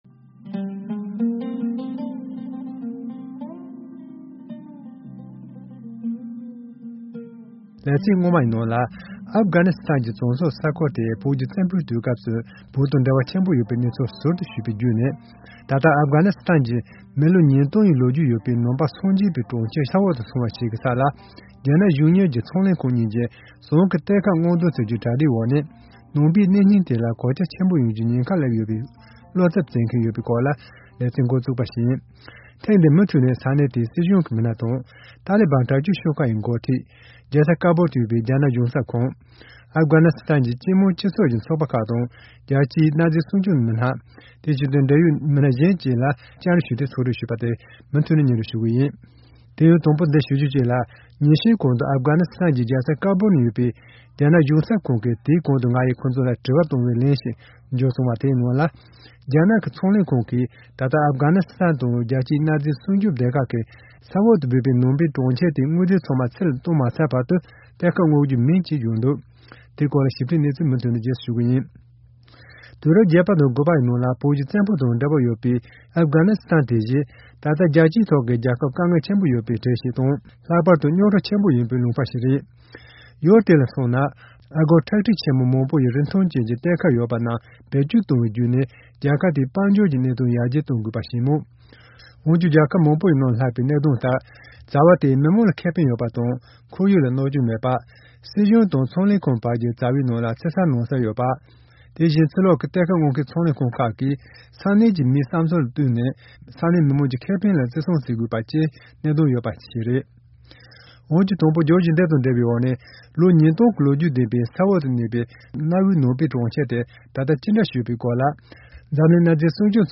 དེ་བཞིན་རྒྱལ་སྤྱིའི་ཞིབ་འཇུག་པ་དང་གནའ་རྫས་རྟོགས་ཞིབ་པ་བ་སོགས་ལ་བཅར་འདྲི་ཞུས་ཏེ་ཕྱོགས་བསྒྲིགས་ཞུས་པ་གཉིས་པ།